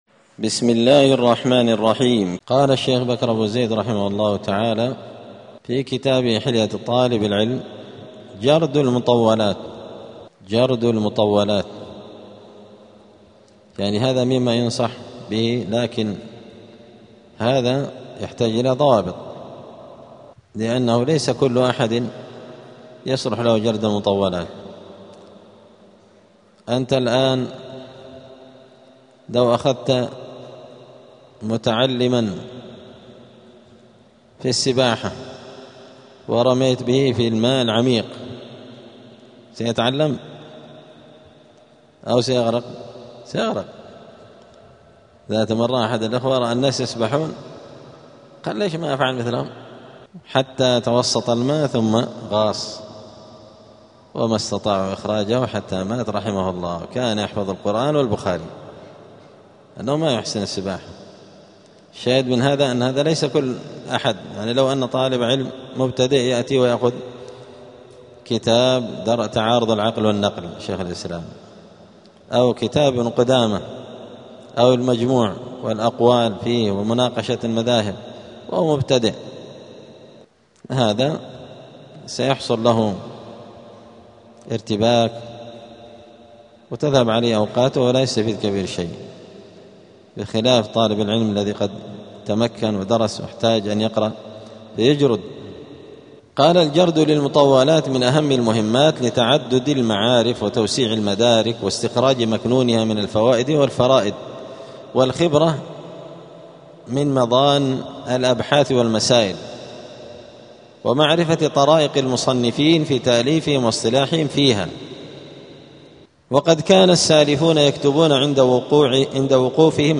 *الدرس الواحد والثمانون (81) فصل آداب الطالب في حياته العلمية {جرد المطولات}.*